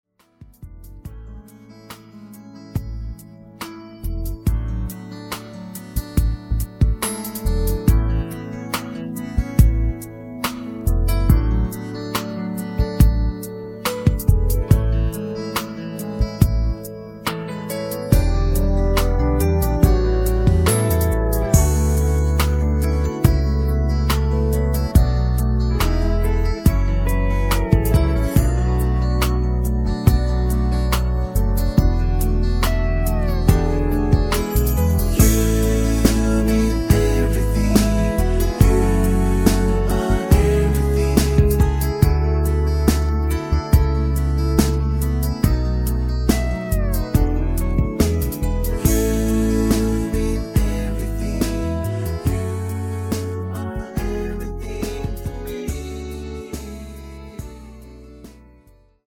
음정 원키 4:36
장르 가요 구분 Voice MR
보이스 MR은 가이드 보컬이 포함되어 있어 유용합니다.